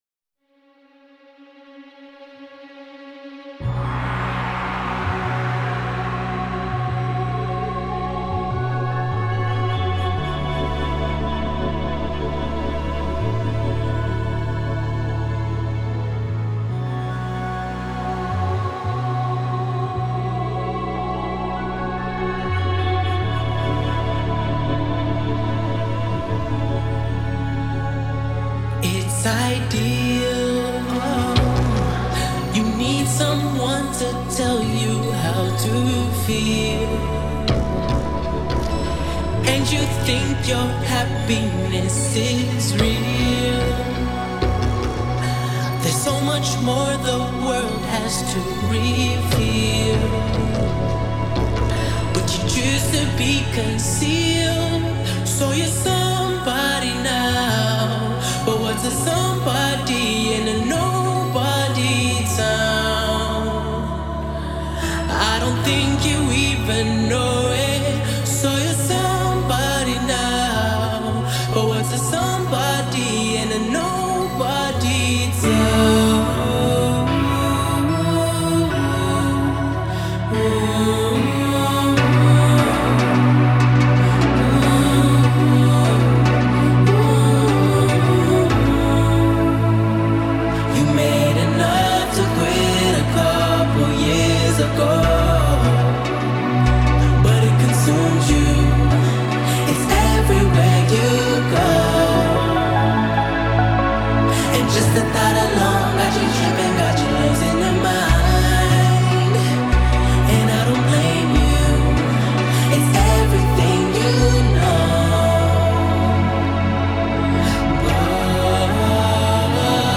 Alternative R&B, Pop, Electronic, Dark Wave